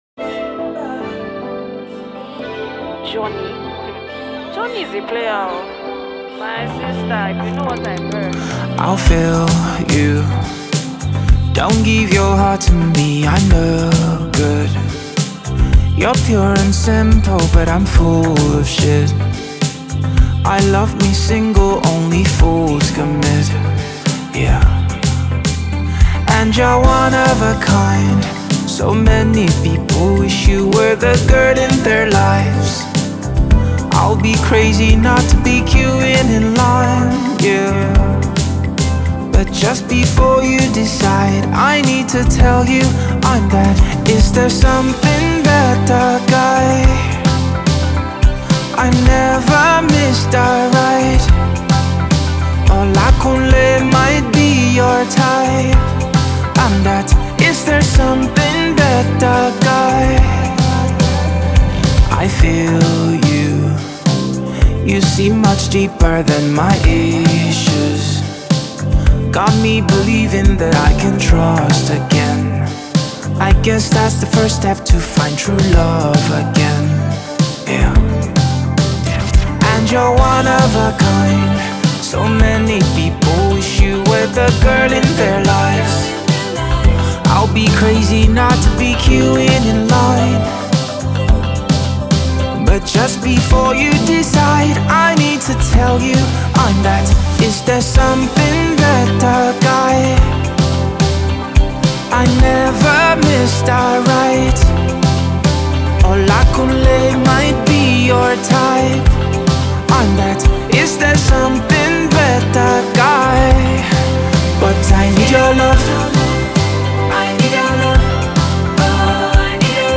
The song is spiced with guitar and a blend of Pop.